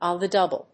アクセントon the dóuble